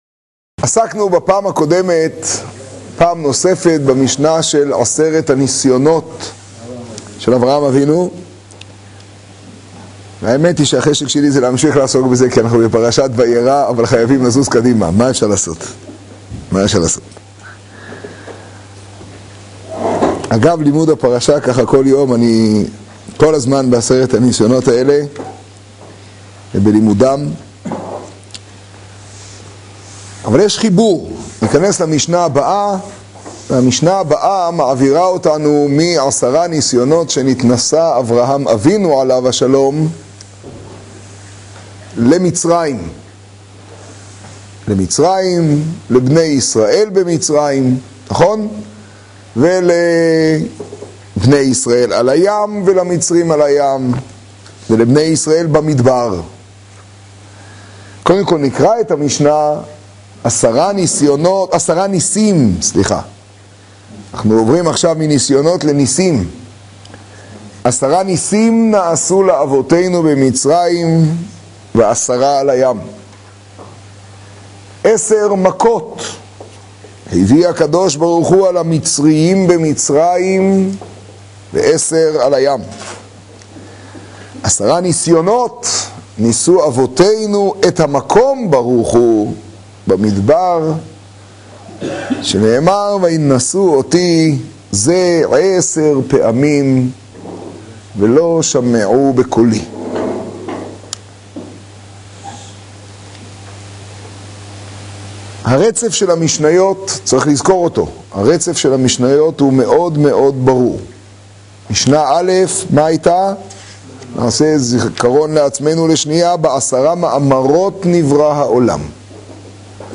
קטגוריה: שיעור , שיעור בחצור , תוכן תג: פרקי אבות ה , תשעג → והאמין בה'…